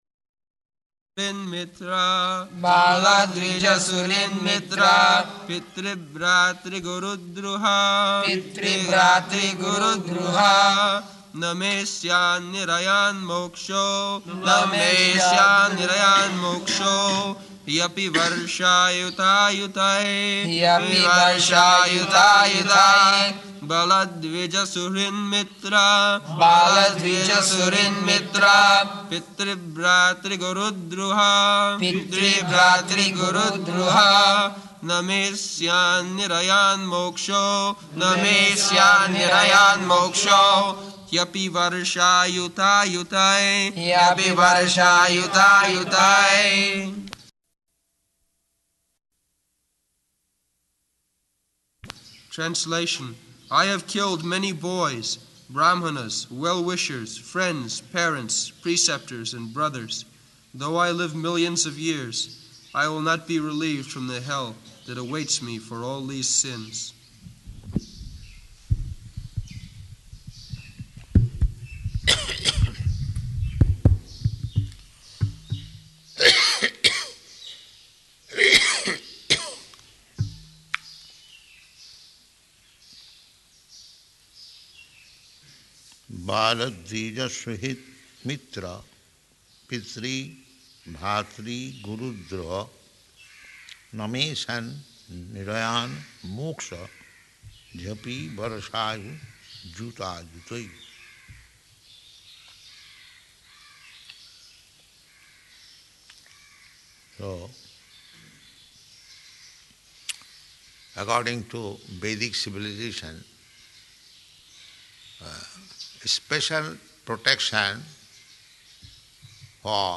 October 29th 1974 Location: Māyāpur Audio file